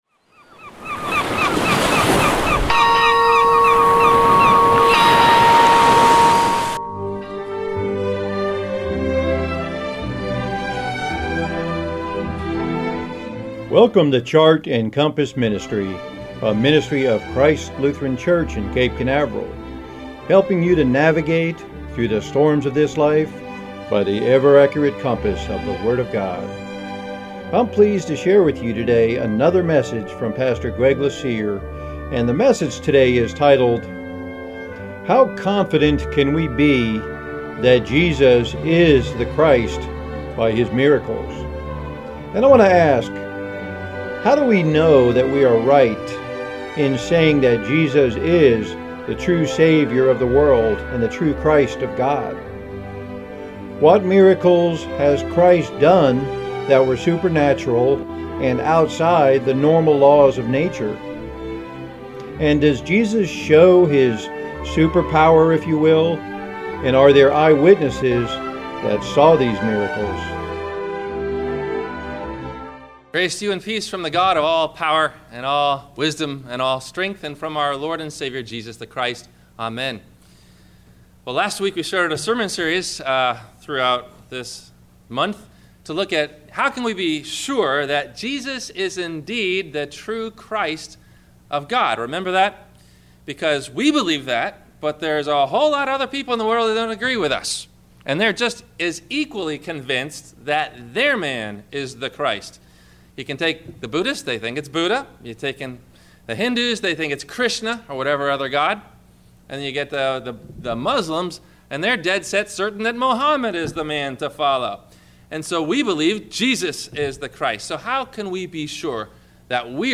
How Confident Can We Be That Jesus is The Christ by His Miracles? – WMIE Radio Sermon – January 04 2016